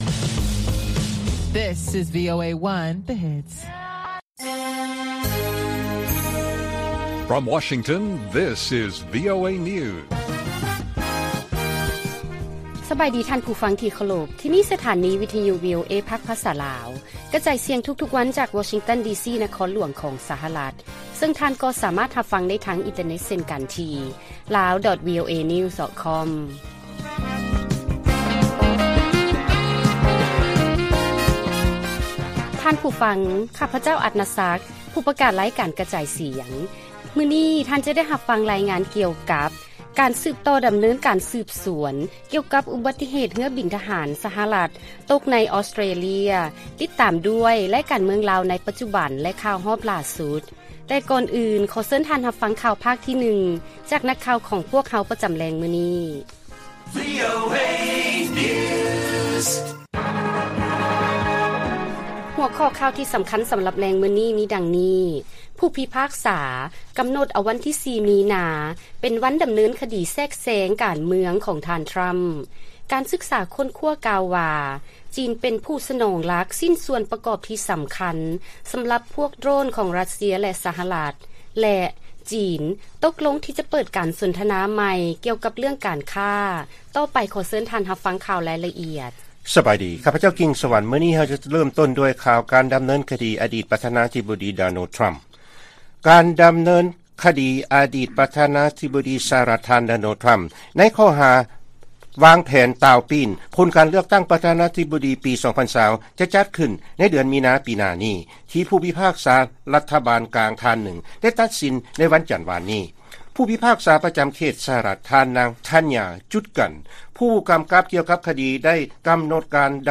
ວີໂອເອພາກພາສາລາວ ກະຈາຍສຽງທຸກໆວັນ, ຫົວຂໍ້ຂ່າວສໍາຄັນໃນມື້ນີ້ມີ: 1. ຜູ້ພິພາກສາ ກຳນົດເອົາວັນທີ 4 ມີນາເປັນວັນດຳເນີນຄະດີຂອງທ່ານທຣໍາ, 2. ການຄົ້ນຄວ້າກ່າວວ່າ ຈີນເປັນຜູ້ສະໜອງຫຼັກໃນຊິ້ນສ່ວນໂດຣນທີ່ເປັນສ່ວນປະກອບສຳຄັນຂອງຣັດເຊຍ, ແລະ 3. ສຫລ ແລະ ຈີນ ຕົກລົງ ທີ່ຈະເປີດການສົນທະນາກັນໃໝ່ ກ່ຽວກັບເລື້ອງການຄ້າ.